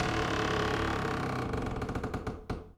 door_A_creak_08.wav